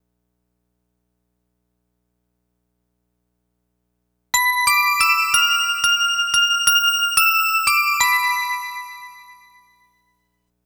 Bells 01.wav